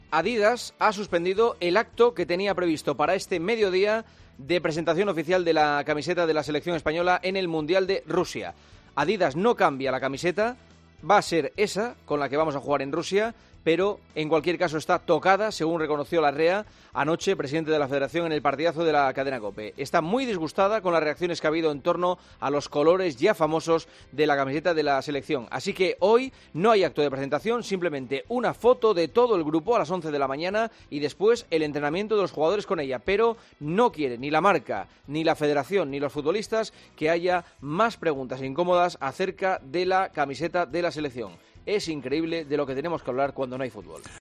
El comentario de Juanma Castaño
La polémica por el color de la nueva camiseta de la Selección, en el comentario de Juanma Castaño en 'Herrera en COPE'.